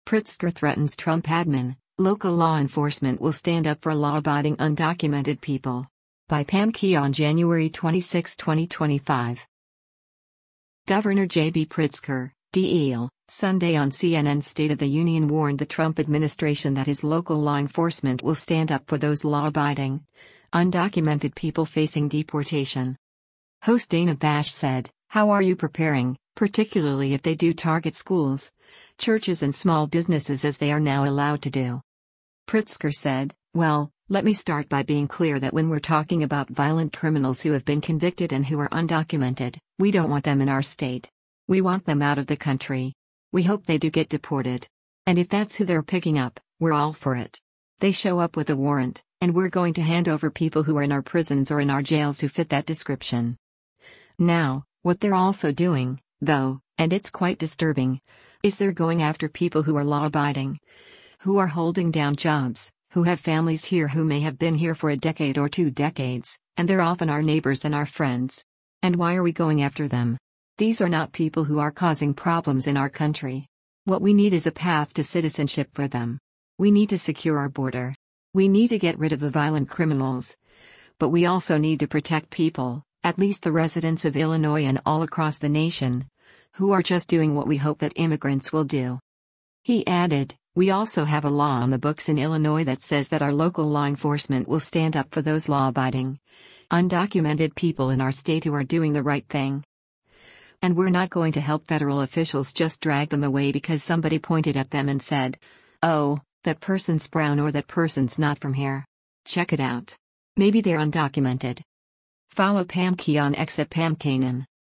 Governor JB Pritzker (D-IL) Sunday on CNN’s “State of the Union” warned the Trump administration that his local law enforcement “will stand up for those law-abiding, undocumented people” facing deportation.